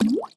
Звуки бульк
Звук бульк когда пельмень кинули в воду